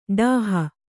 ♪ ḍāha